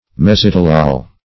Meaning of mesitylol. mesitylol synonyms, pronunciation, spelling and more from Free Dictionary.